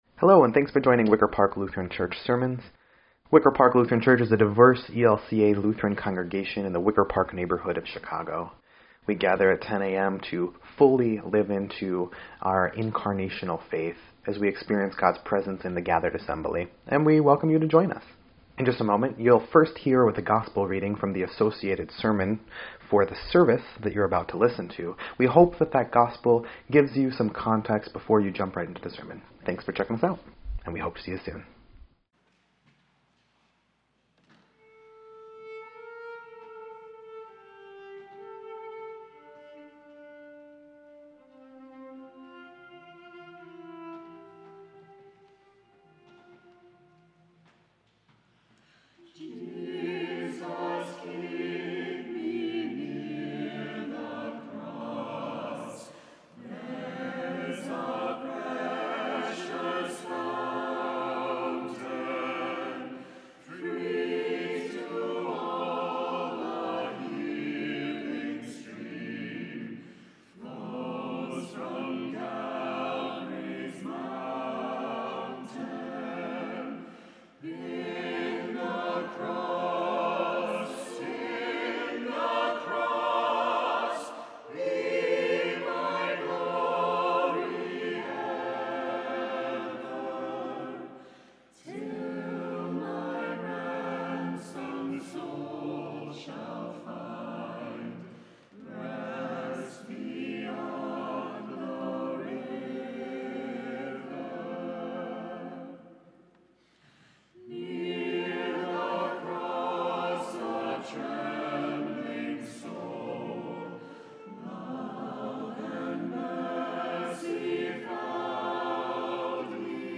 4.18.25-Sermon_EDIT.mp3